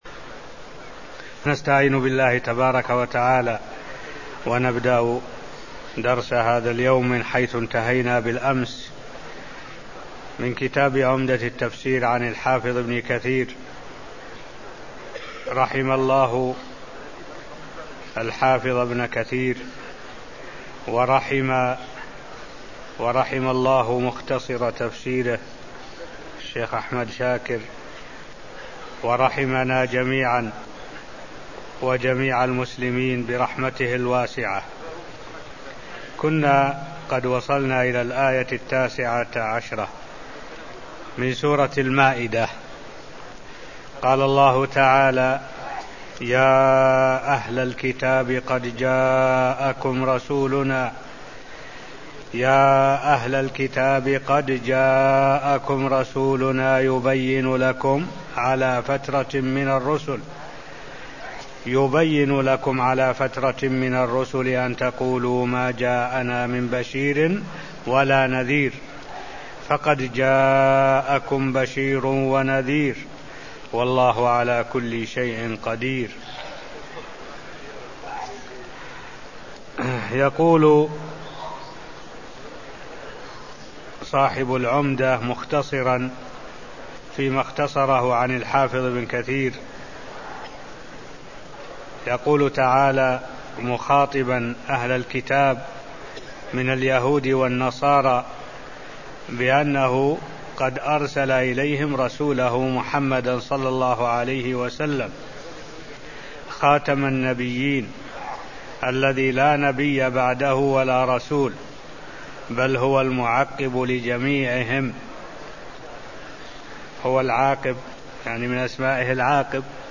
المكان: المسجد النبوي الشيخ: معالي الشيخ الدكتور صالح بن عبد الله العبود معالي الشيخ الدكتور صالح بن عبد الله العبود تفسير سورة المائدة آية 19 (0238)